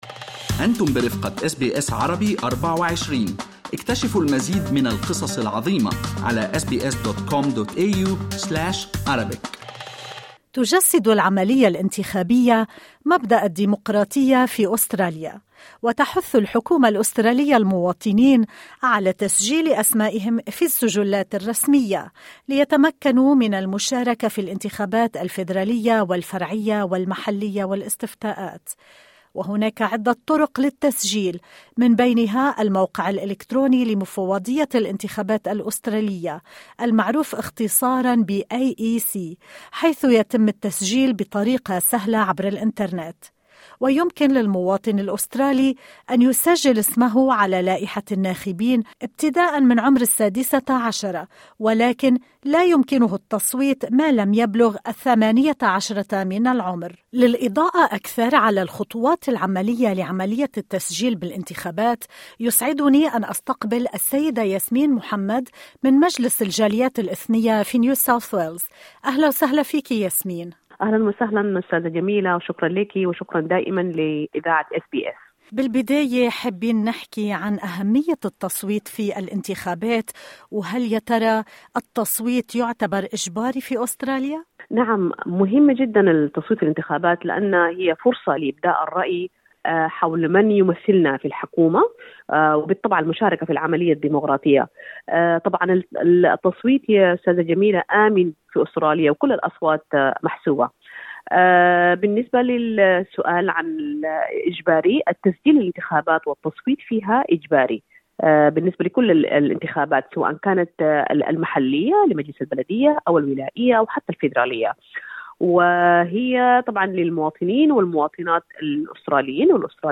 في لقاء لها مع أس بي أس عربي24